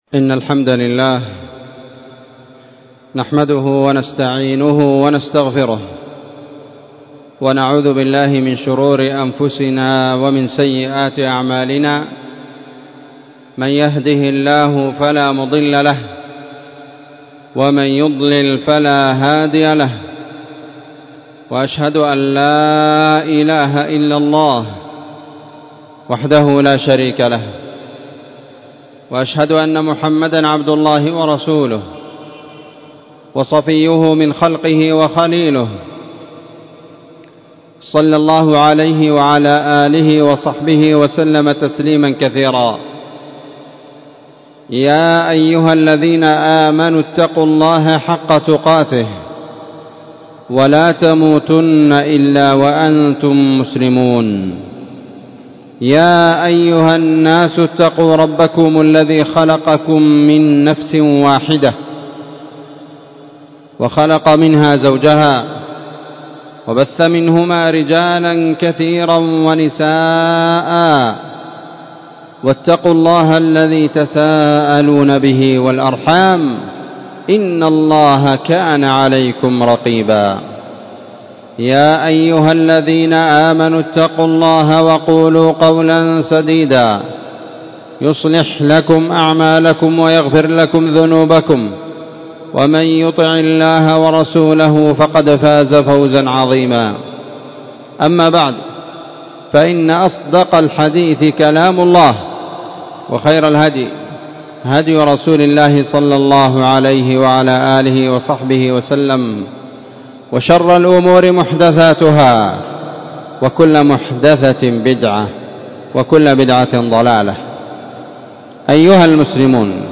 خطبة جمعة
في مسجد المجاهد- النسيرية- تعز